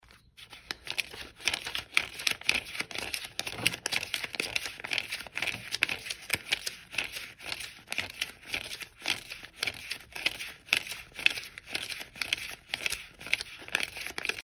Звуки долларов
6. Перебирают купюры